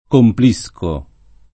complire v.; complo [k0mplo], -pli (più raro complisco [